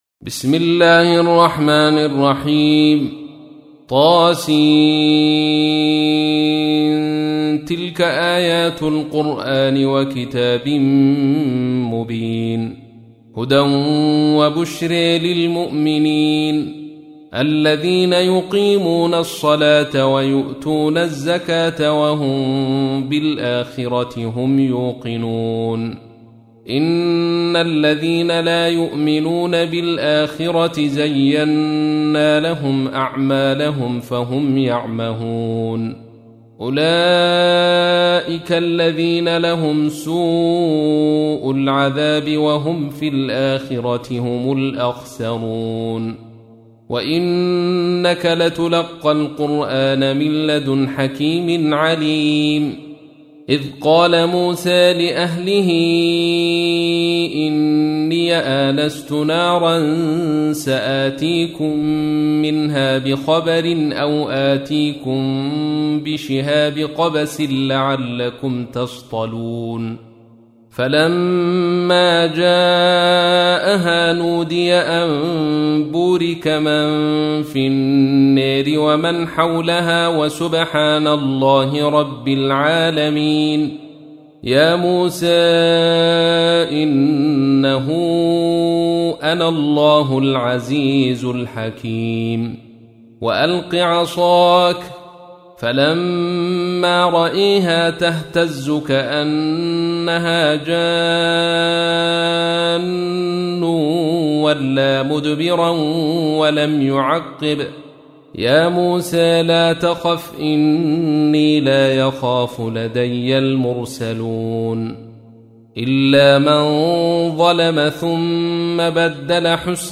تحميل : 27. سورة النمل / القارئ عبد الرشيد صوفي / القرآن الكريم / موقع يا حسين